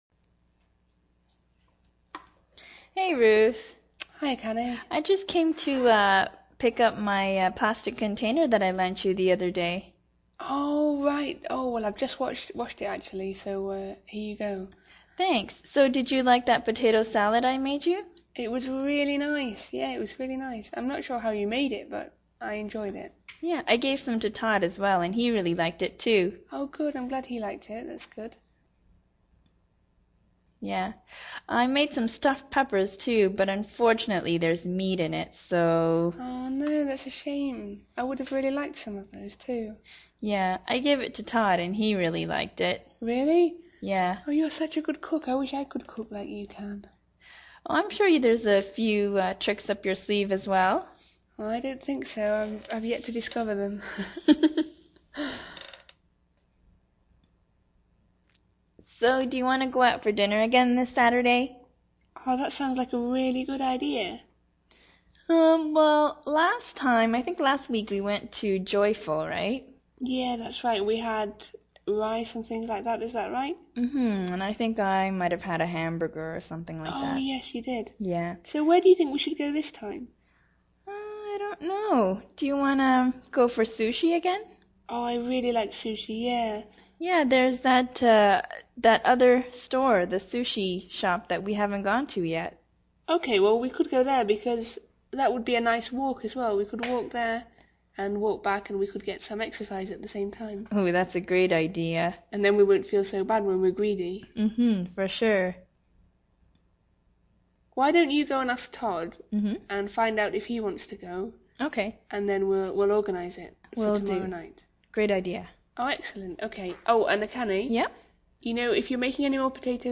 英语访谈对话 538 Dinner 听力文件下载—在线英语听力室